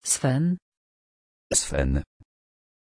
Aussprache von Sven
pronunciation-sven-pl.mp3